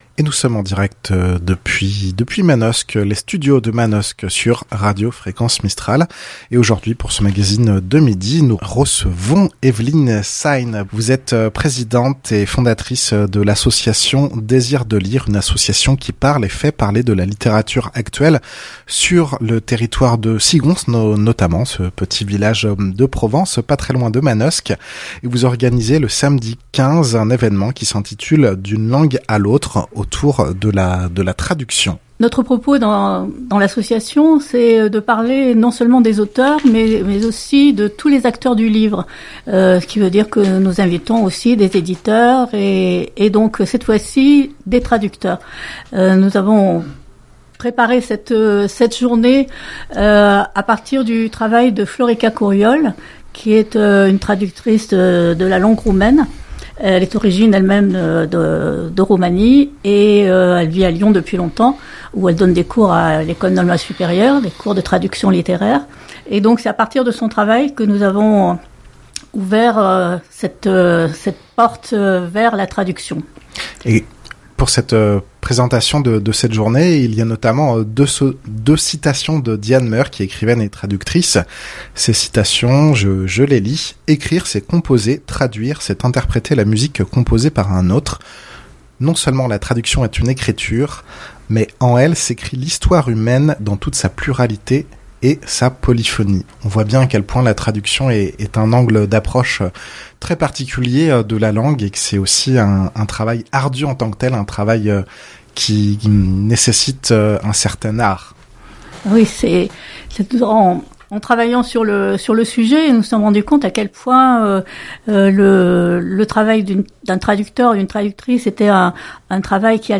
présente ce rendez-vous en direct depuis les studios de Fréquence Mistral à Manosque. Interview